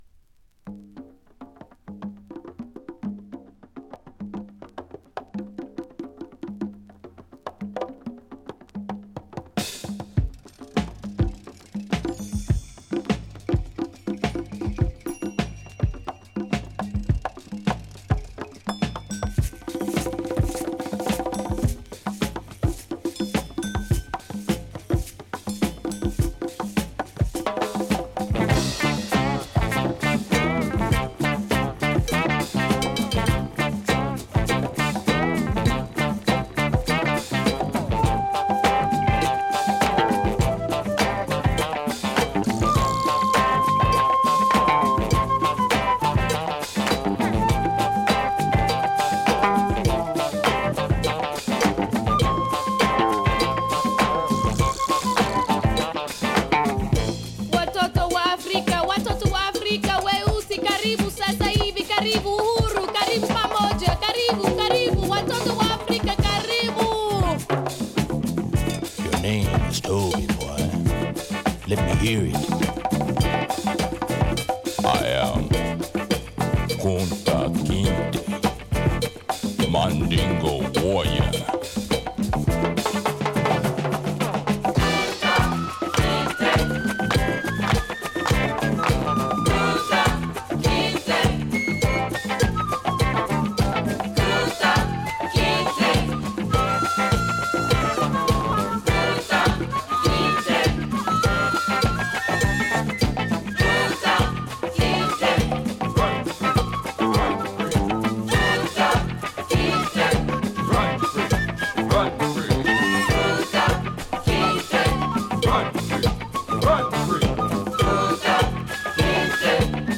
73902 ◆US盤 7"Single 45 RPM 現物の試聴（両面すべて録音時間７分５０秒７分５０秒）できます。
中盤にB-Boyブレイクも入るグレイトアフロファンク